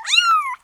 kitten.wav